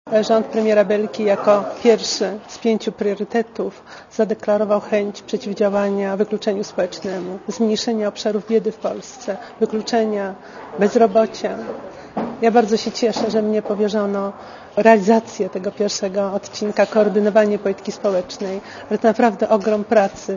Posłuchaj komentarza Izebeli Jarugi-Nowackiej